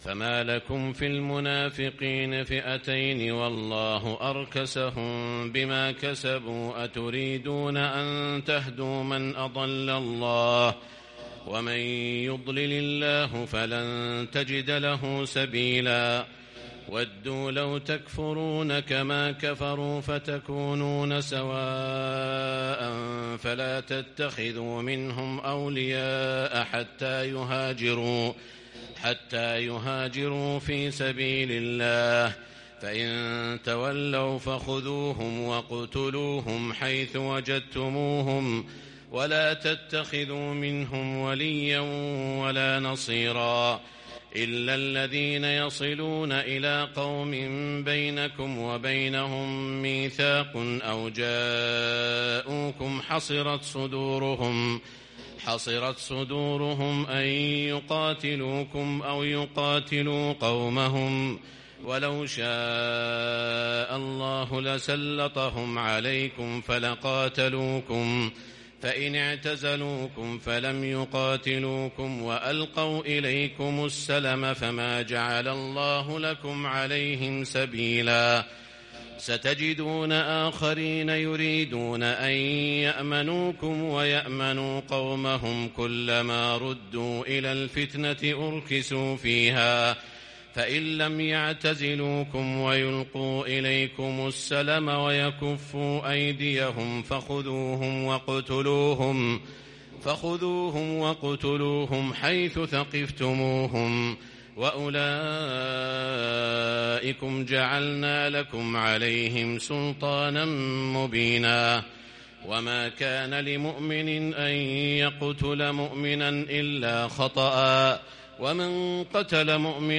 تراويح ليلة 7 رمضان 1441هـ من سورة النساء {88-134} Taraweeh 7st night Ramadan 1441H Surah An-Nisaa > تراويح الحرم المكي عام 1441 🕋 > التراويح - تلاوات الحرمين